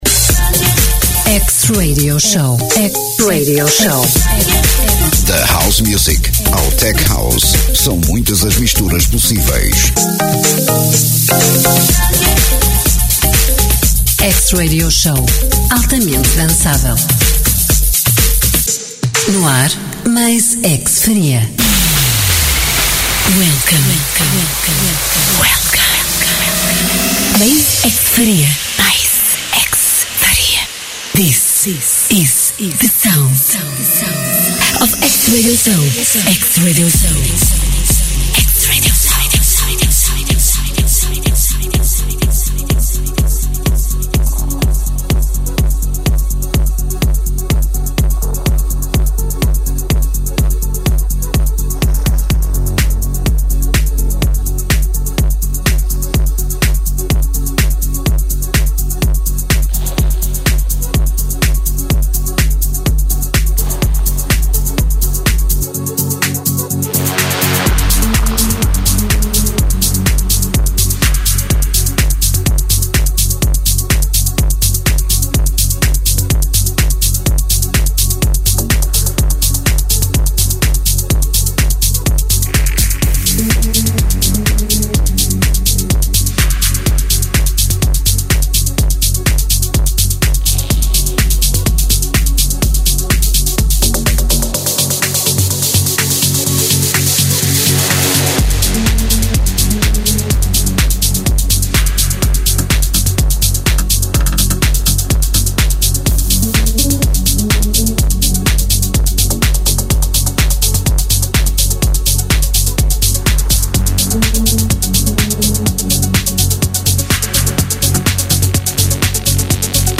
House Music in the mix